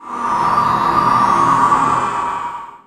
Magic_DoorOpen05.wav